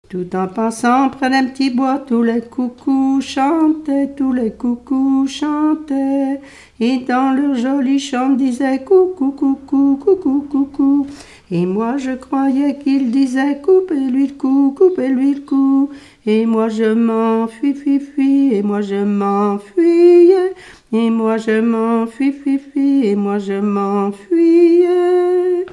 chanteur(s), chant, chanson, chansonnette
Aubigny
Pièce musicale inédite